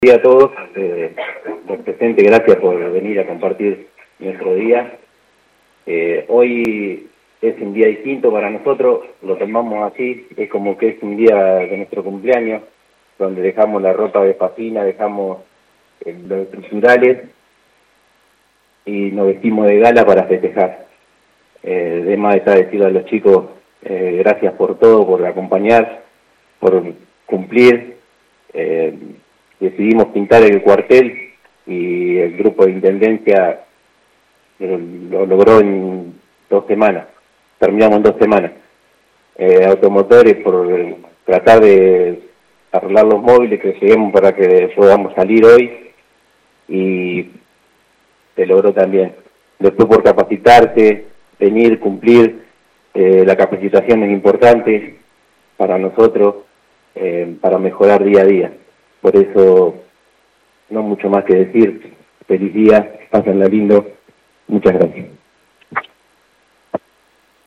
Con la presencia de la actual Comisión Directiva, integrantes del Cuerpo Activo, Cuerpo de Reserva y autoridades municipales se llevó a cabo en la mañana de hoy en el cuartel de calle Pueyrredón el acto conmemorativo por el Día del Bombero Voluntario.